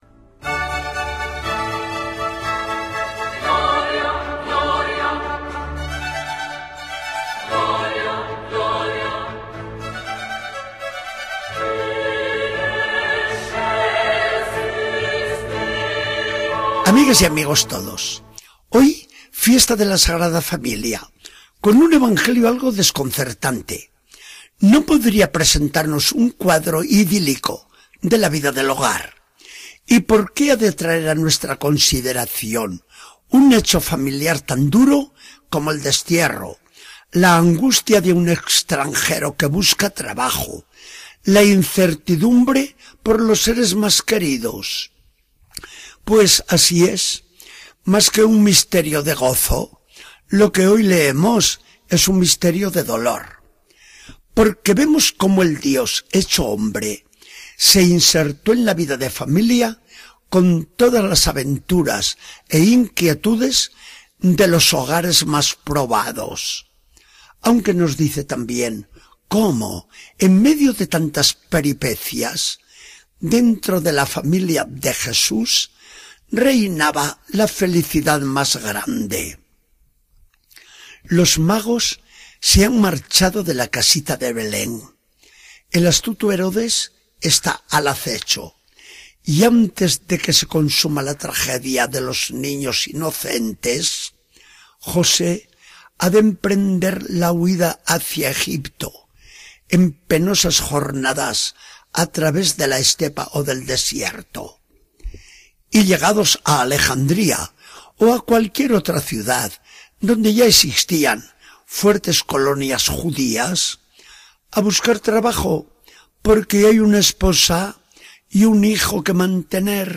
Charla del día 29 de diciembre de 2013. Del Evangelio según San Mateo.